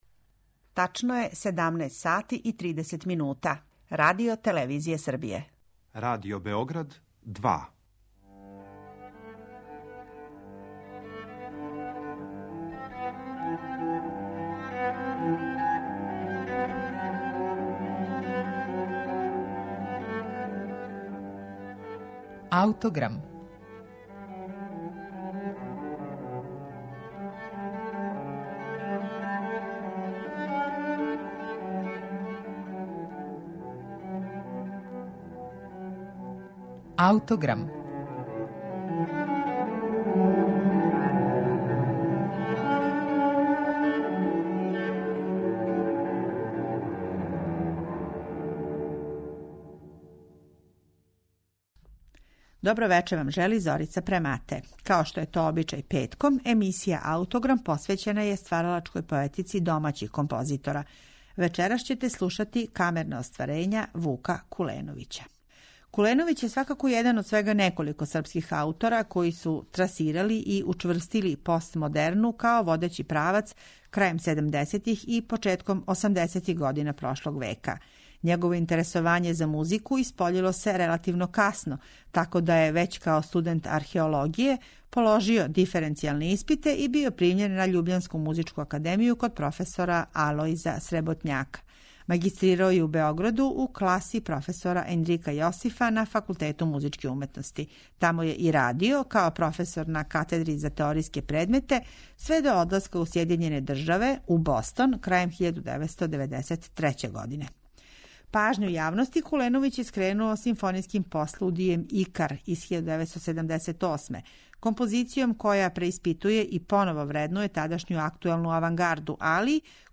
Камерна дела
за инструментални ансамбл
Слушаћете снимак са премијерног извођења овог дела